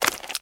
STEPS Swamp, Walk 27.wav